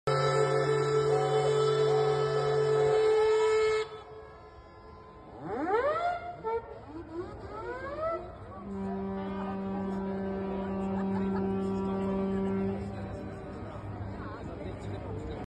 every ship blasting their horns, while the old steamers puff clouds of smoke across the IJ.